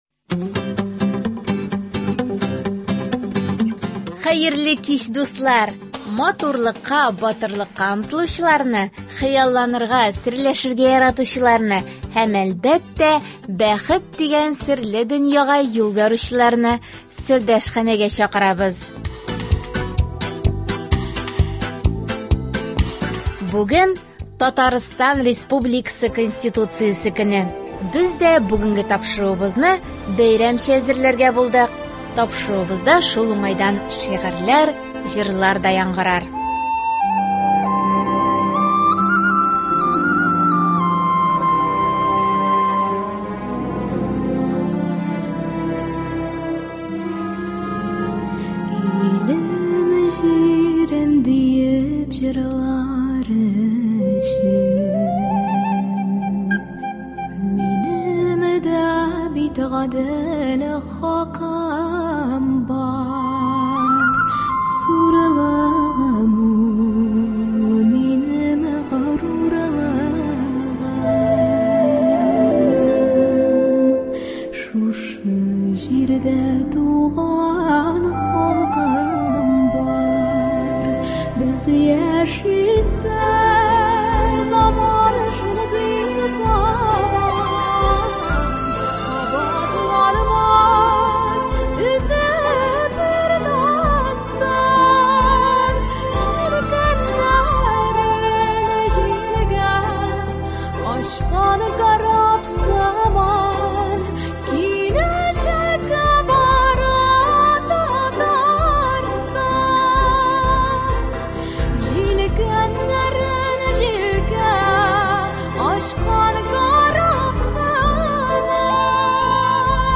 Тапшыруыбызда шул уңайдан шигырьләр, җырлар да яңгырар.